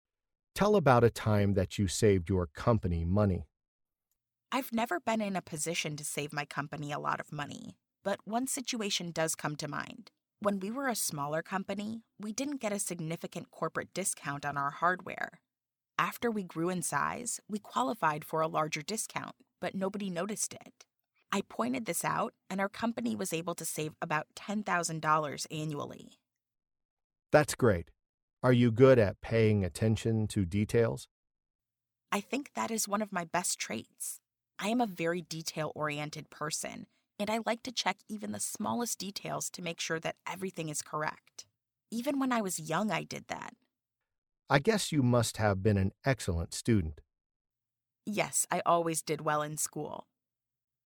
Learn different ways to answer the interview question 'Tell about a time that you saved your company money.', listen to an example conversation, and study example sentences.